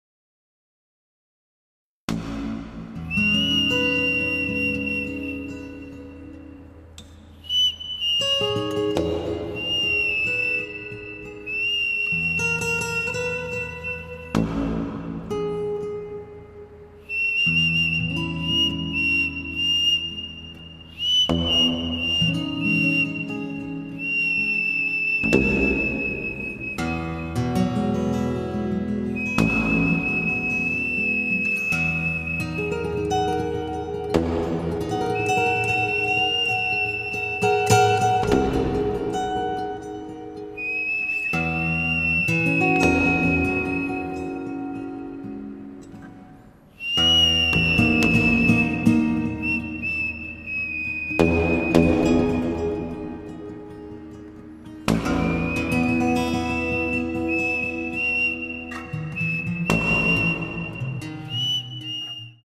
at studio Voice
石笛、横竹太鼓、ギター